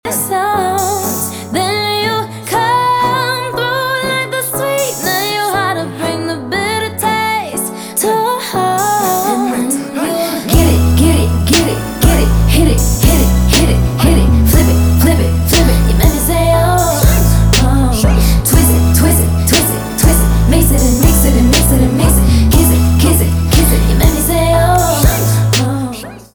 поп
rnb